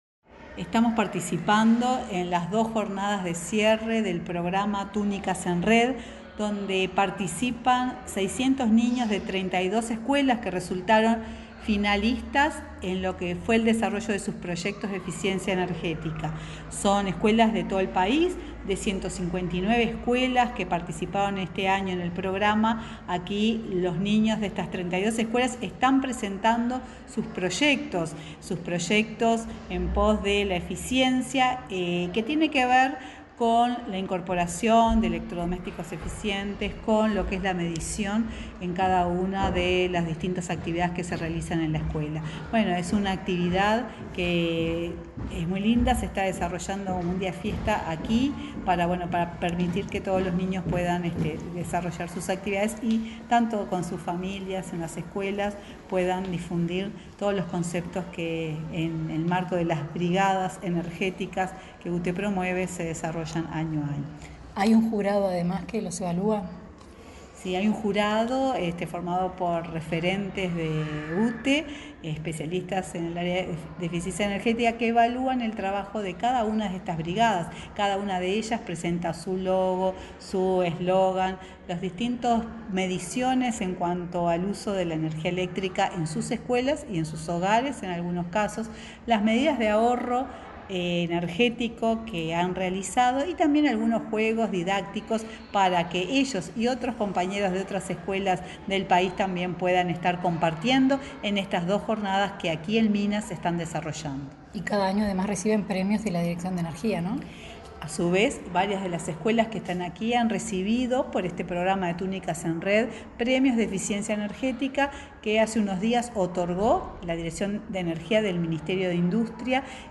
Declaraciones de la presidenta de UTE, Silvia Emaldi